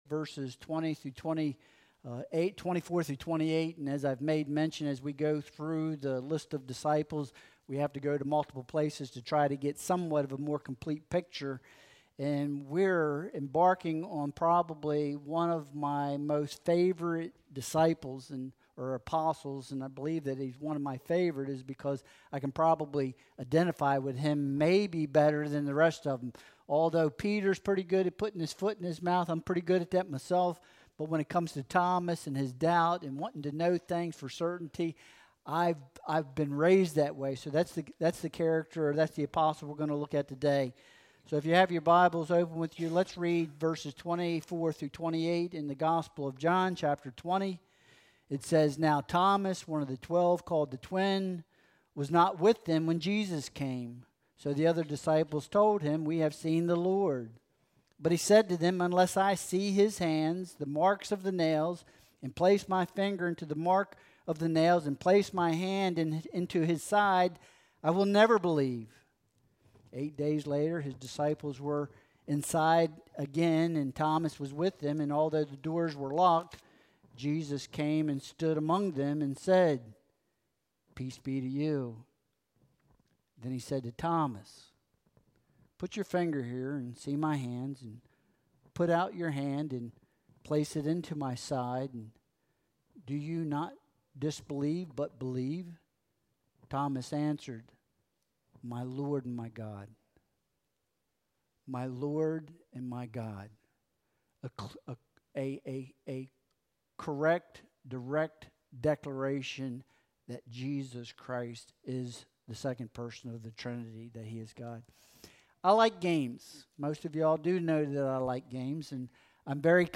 John 20.24-28 Service Type: Sunday Worship Service Download Files Bulletin « Disaster Relief Ministry